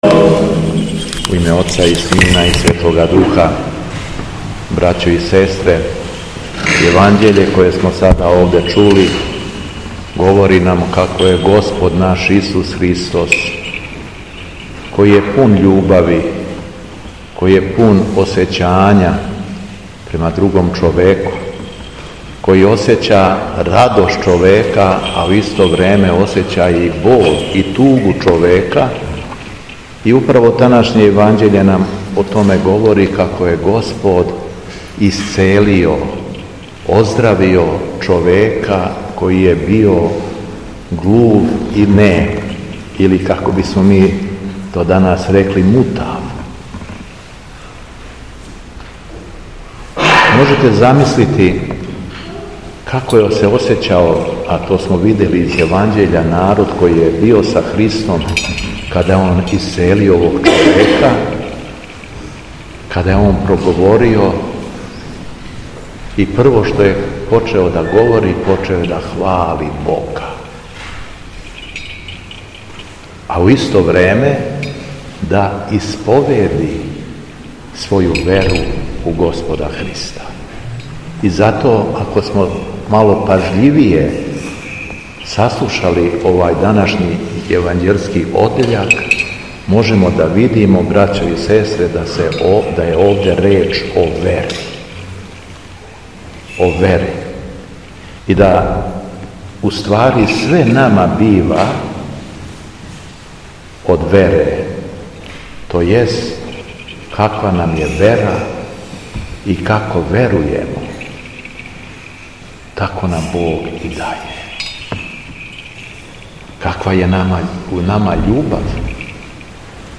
СВЕТА АРХИЈЕРЕЈСКА ЛИТУРГИЈА У ЈАГОДИНСКОЈ БОГОРОДИЧИНОЈ ЦРКВИ - Епархија Шумадијска
Беседа Његовог Високопреосвештенства Митрополита шумадијског г. Јована